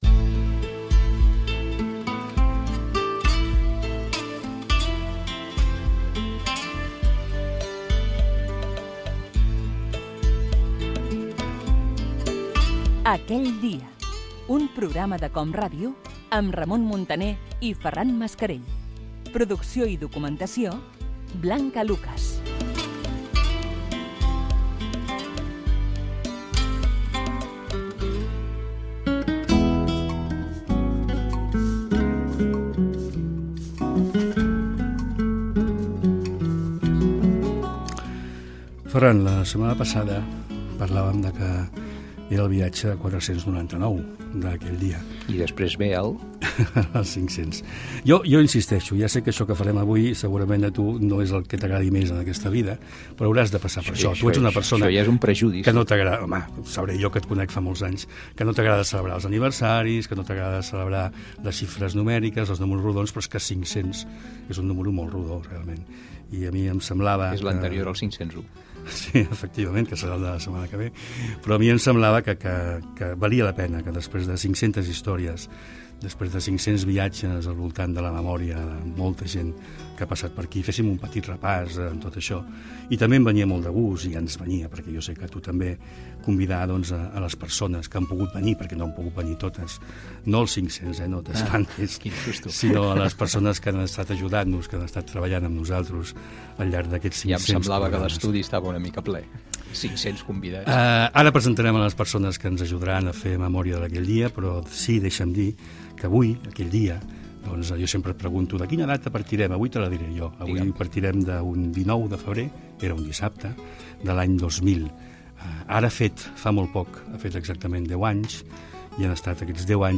Indicatiu del programa. Presentació de l'edició 500 del programa. La data de la qual es parla és el 19 de febrer de l'any 2000.
Entreteniment